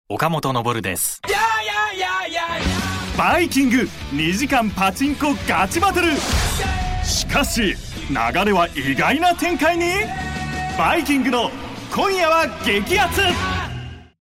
クイズ・ショー・情報 (テンション高め)